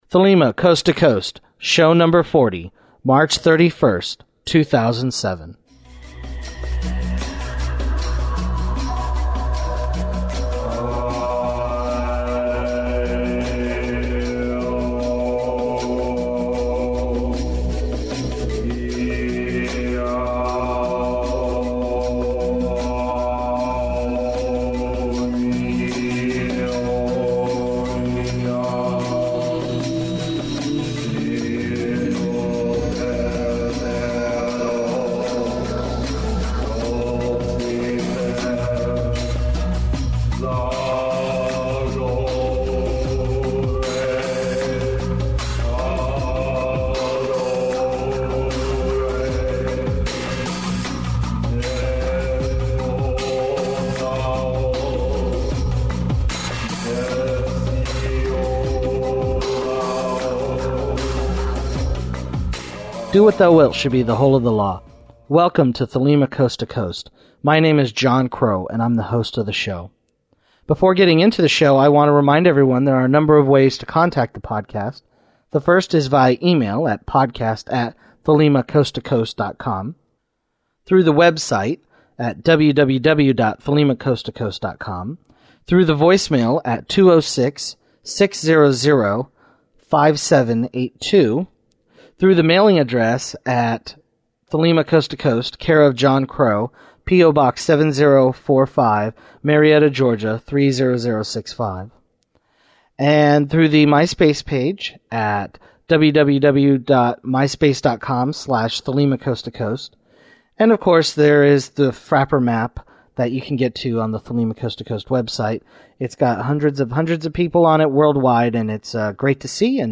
Opening remarks Listener feedback